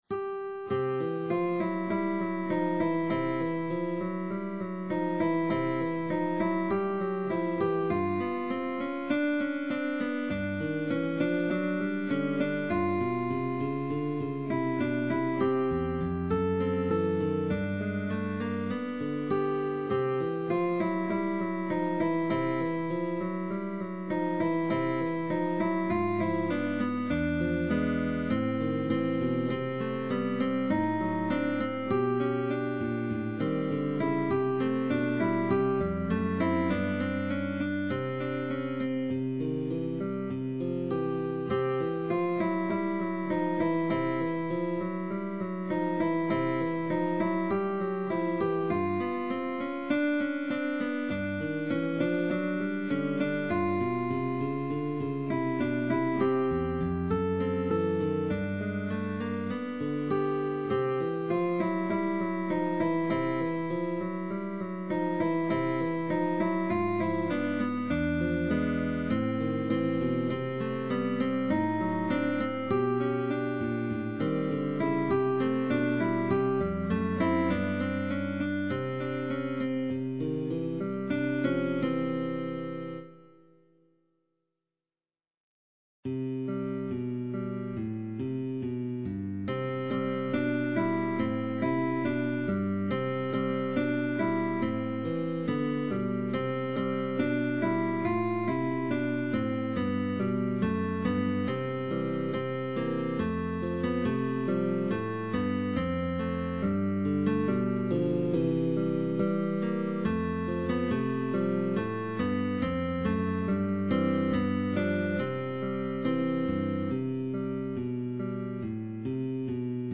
GUITAR DUO. Pupil & Teacher